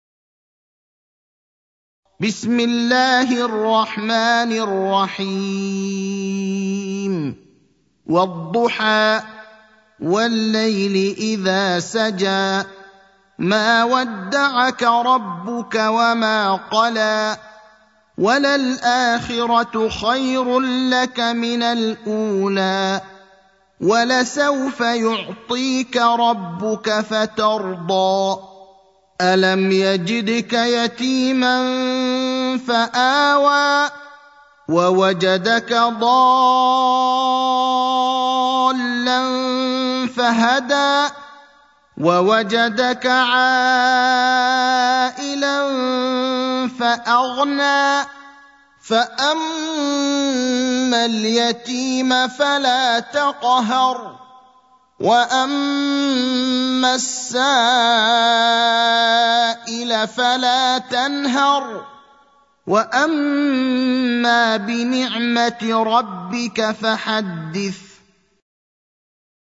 المكان: المسجد النبوي الشيخ: فضيلة الشيخ إبراهيم الأخضر فضيلة الشيخ إبراهيم الأخضر الضحى (93)